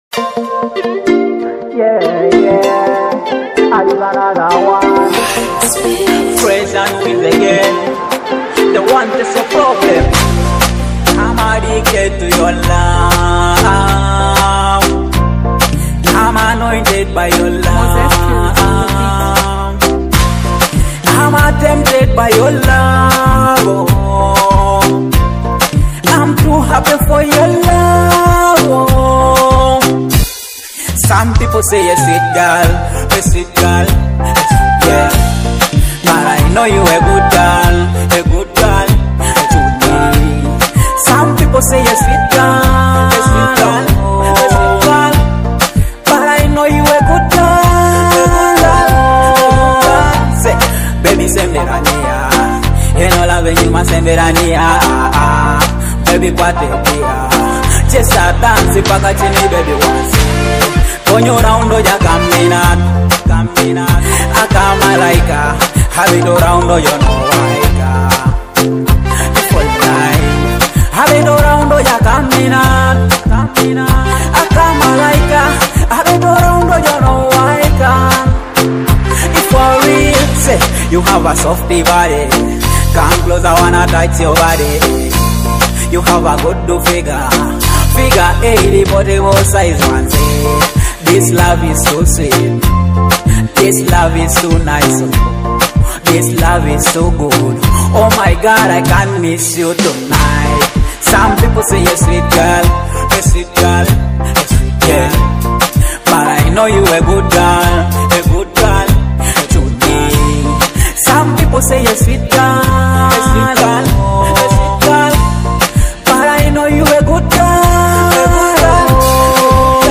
a catchy dancehall hit with heartfelt lyrics